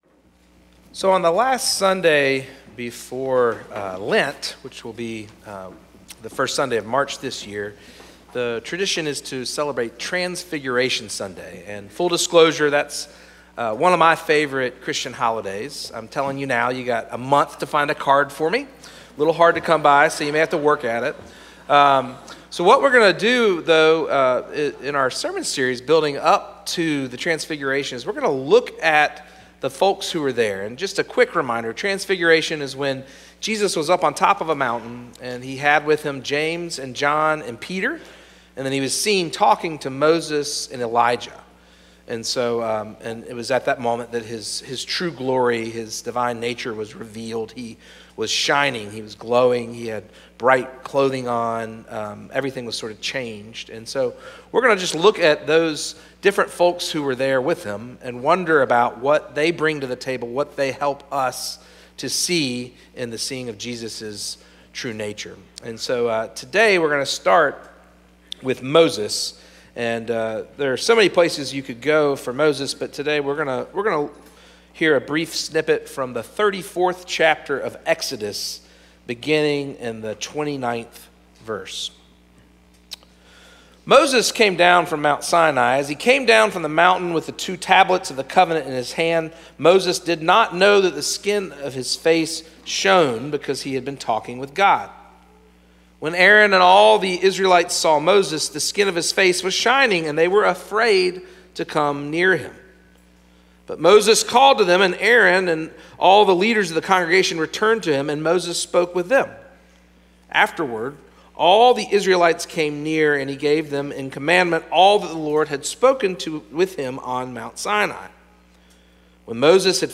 First Cary UMC's First Sanctuary Sermon "Here for God's Promise" &ndash